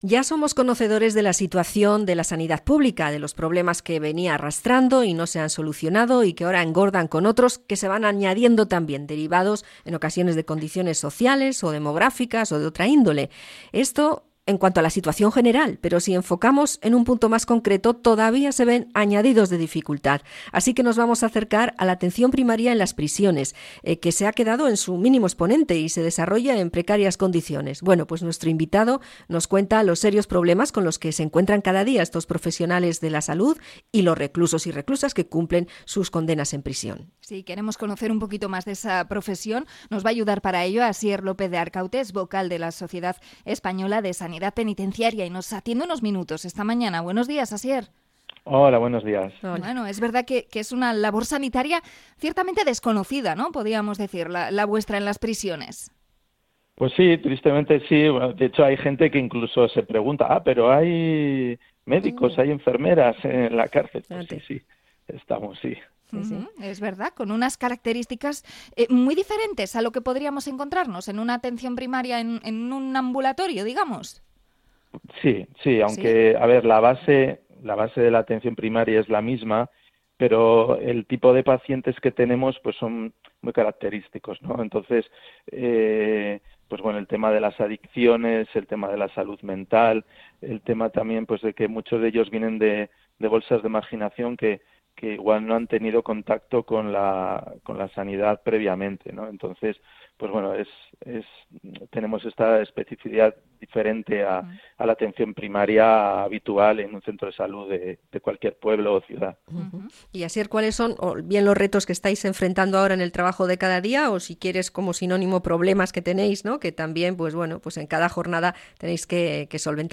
Entrevista a la Sociedad Española de Sanidad Penitenciaria